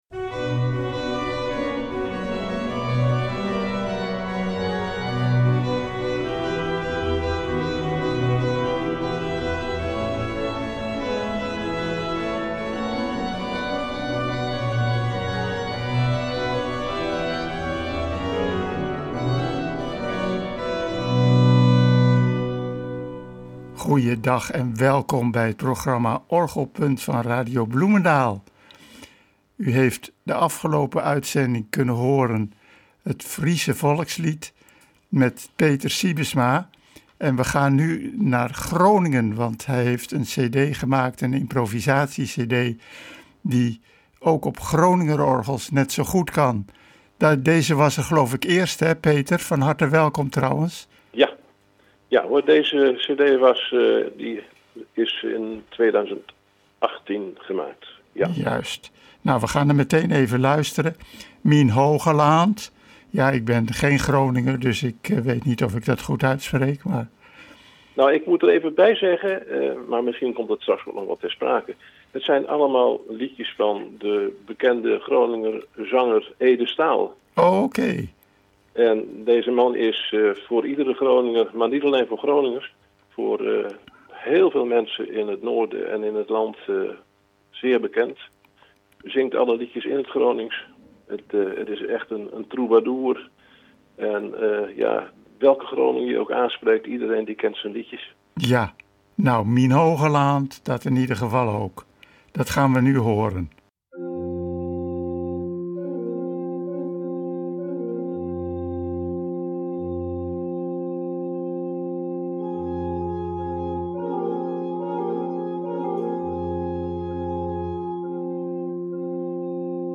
Orgelimprovisaties op Groningse liedjes
orgelimprovisaties op Groningse liedjes
op allerlei fraaie dorpsorgels in de provincie Groningen